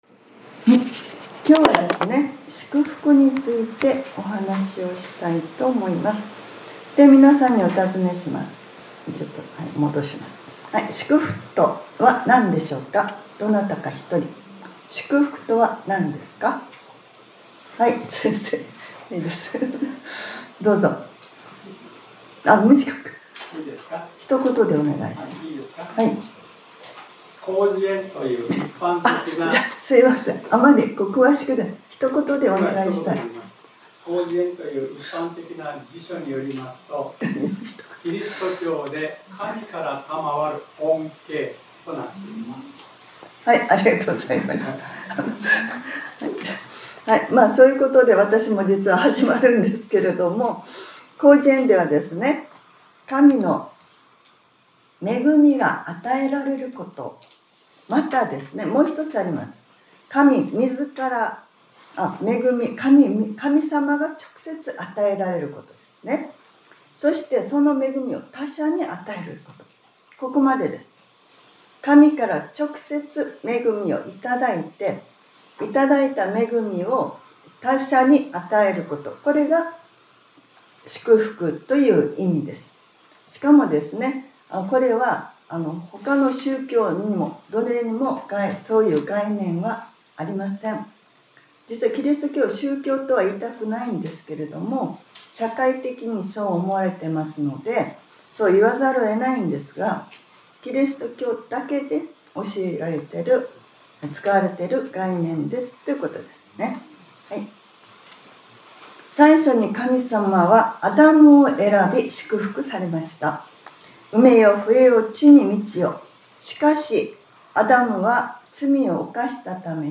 2026年02月15日（日）礼拝説教『 神の祝福 』